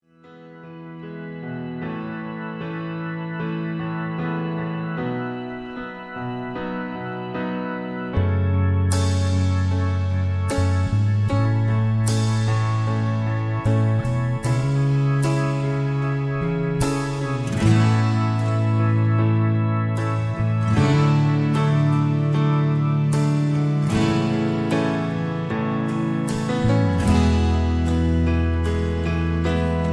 (Key-E) Karaoke MP3 Backing Tracks